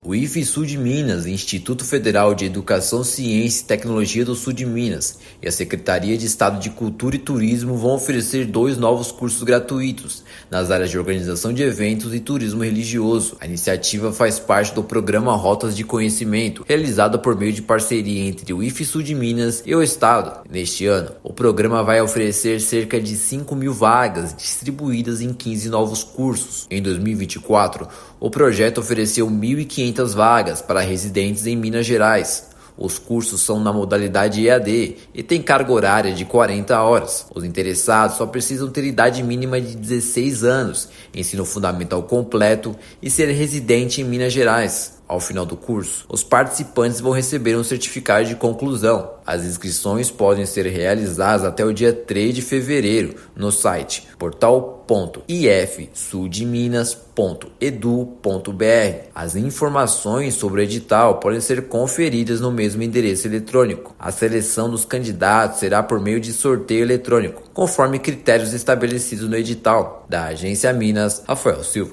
São 650 vagas e as inscrições podem ser feitas até o dia 3/2. O edital para seleção de professores também foi lançado. Ouça matéria de rádio.